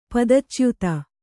♪ padachyuta